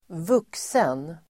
Uttal: [²v'uk:sen]